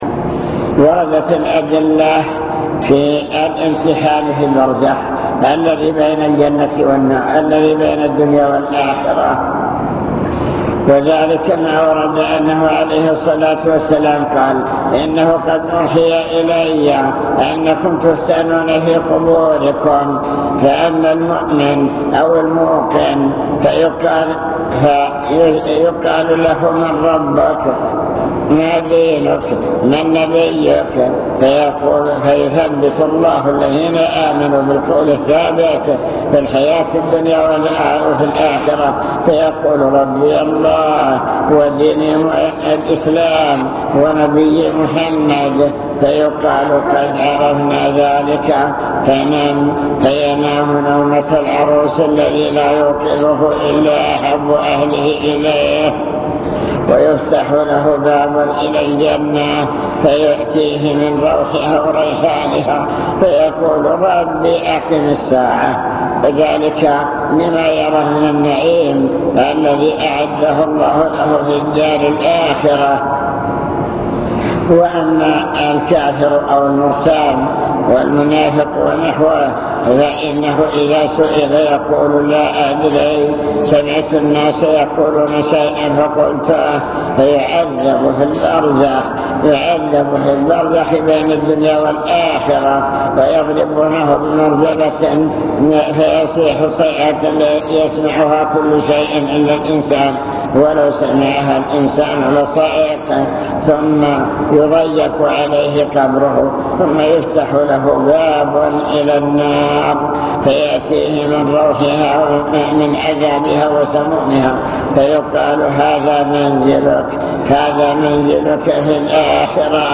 المكتبة الصوتية  تسجيلات - محاضرات ودروس  مواعظ وذكرى